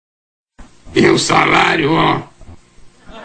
Professor Raimundo e seu mais famoso bordão: e o salário, ó!